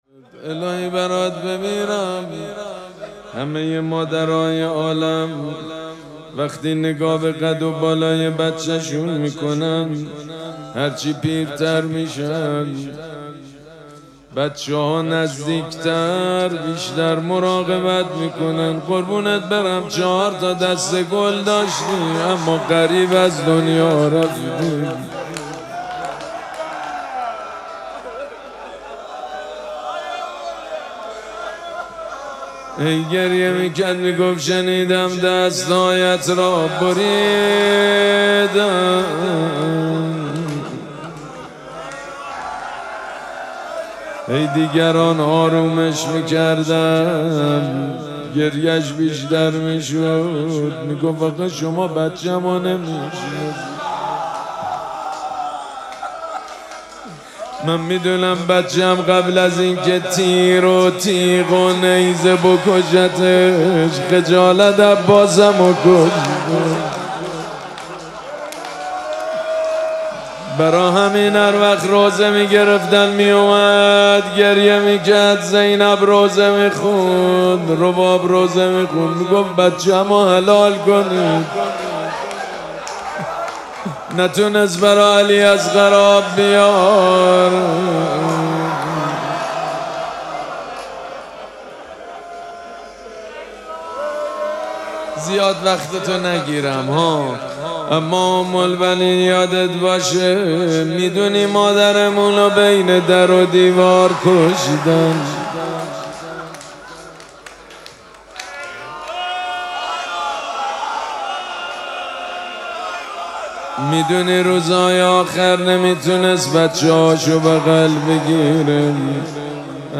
مراسم عزاداری وفات حضرت ام‌البنین سلام‌الله‌علیها
حسینیه ریحانه الحسین سلام الله علیها
روضه